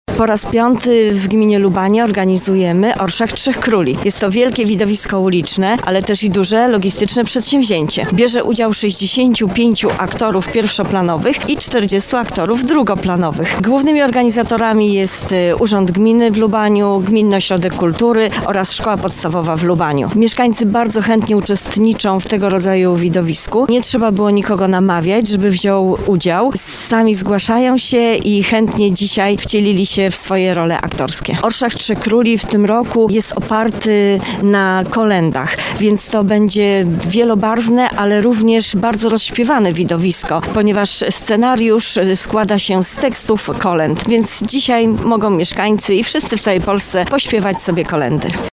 ORSZAK-TRZECH-KRÓLI-MÓWI-WÓJT-GMINY-LUBANIE-LARYSA-KRZYŻAŃSKA.mp3